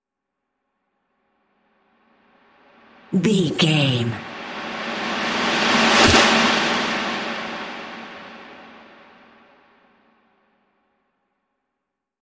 Atonal
E♭
drums
electric guitar
bass guitar
hard rock
aggressive
energetic
intense
nu metal
alternative metal